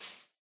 Sound / Minecraft / step / grass1